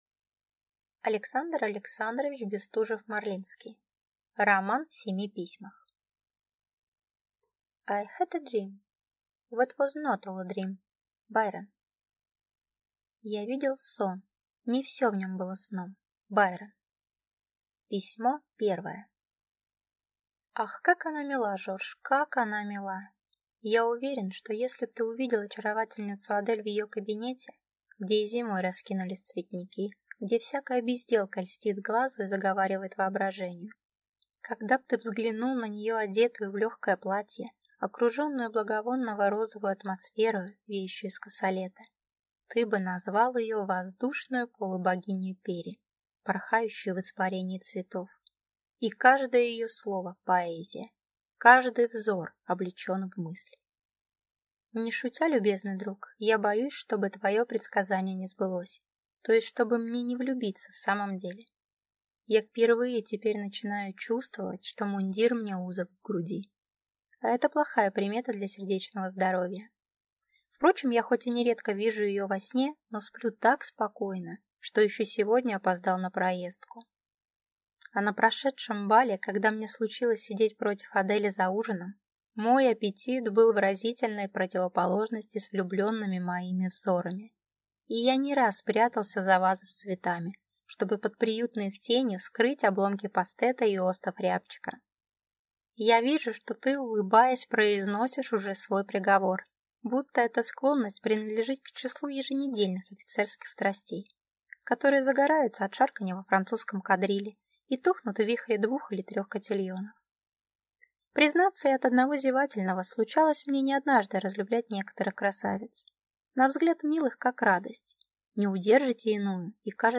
Аудиокнига Роман в семи письмах | Библиотека аудиокниг